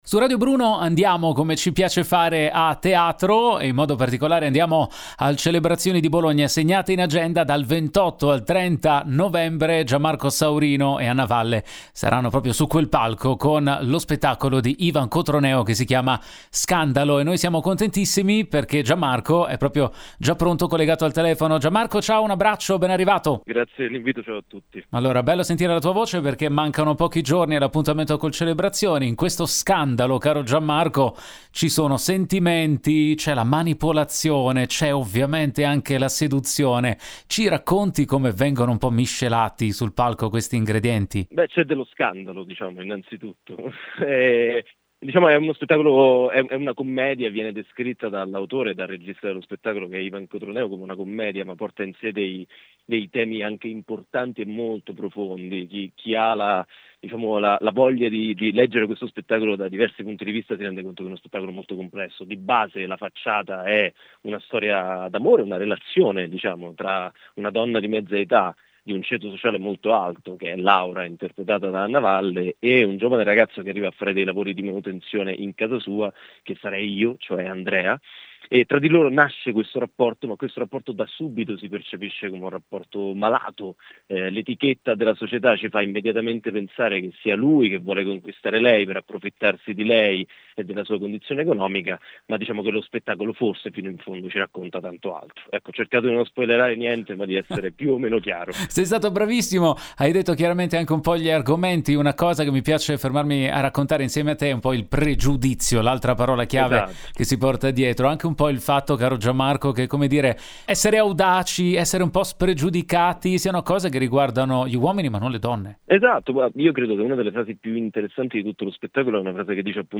Home Magazine Interviste Gianmarco Saurino e Anna Valle a Bologna con “Scandalo”